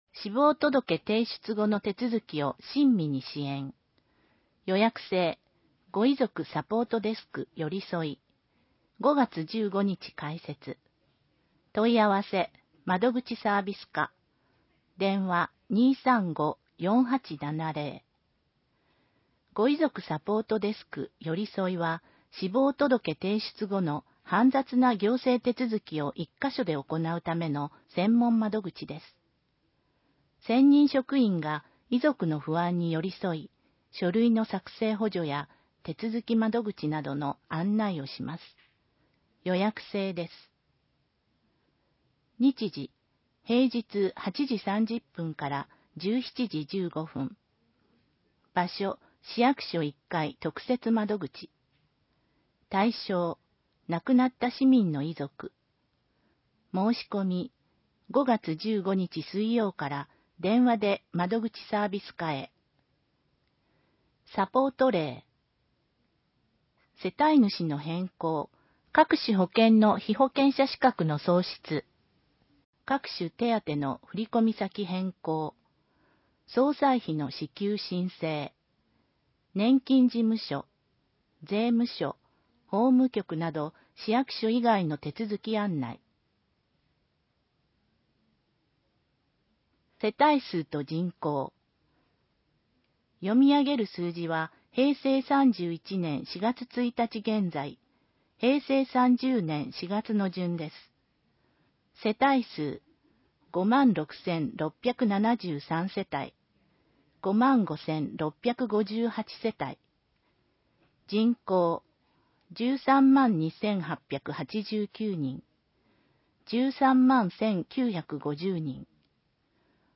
広報えびな 令和元年5月1日号（電子ブック） （外部リンク） PDF・音声版 ※音声版は、音声訳ボランティア「矢ぐるまの会」の協力により、同会が視覚障がい者の方のために作成したものを登載しています。